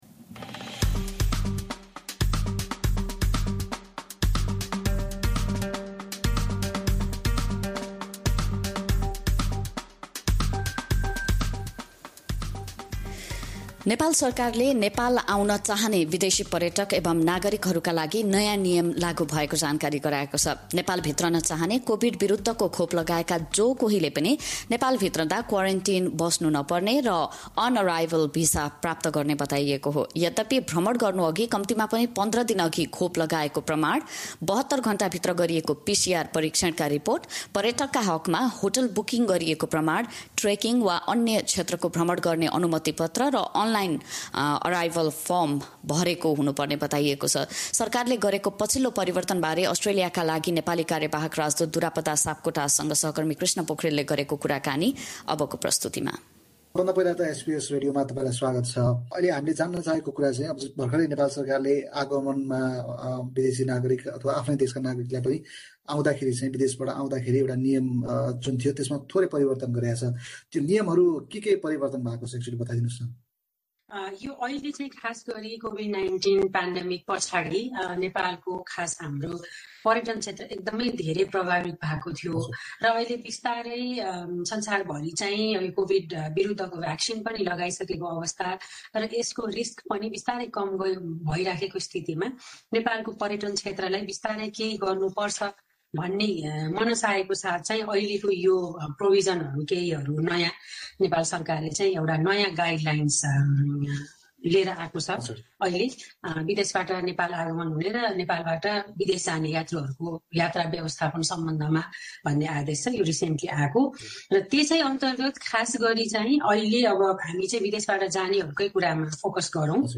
नेपाल जान चाहने नागरिक तथा विदेशी पर्यटकहरूले के-के कुरामा ध्यान राख्नु पर्ने छ? यसबारे अस्ट्रेलियाका लागि नेपाली कार्यवाहक राजदूत दुरापदा सापकोटासँग गरिएको कुराकानी सुन्नुहोस्।